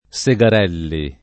Segarelli [ S e g ar $ lli ] cogn.